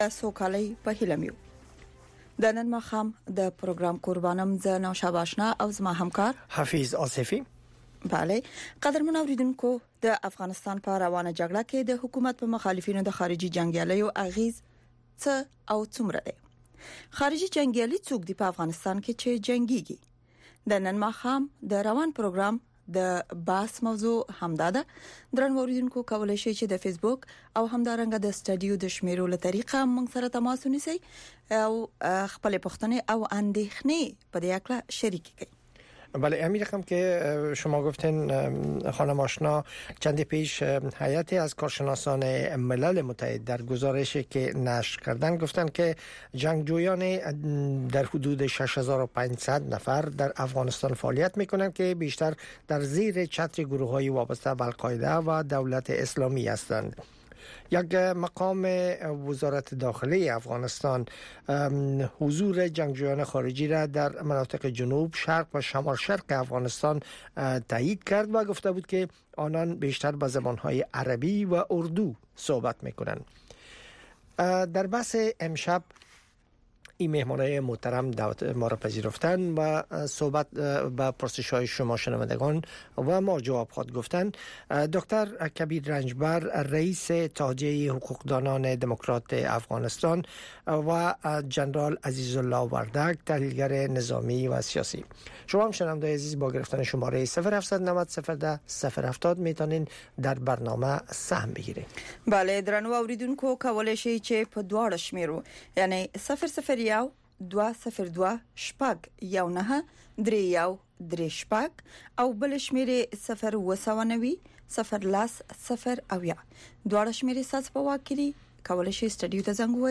گفت و شنود - خبرې اترې، بحث رادیویی در ساعت ۸ شب به وقت افغانستان به زبان های دری و پشتو است. در این برنامه، موضوعات مهم خبری هفته با حضور تحلیلگران و مقام های حکومت افغانستان بحث می شود.